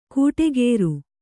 ♪ kūṭe gēru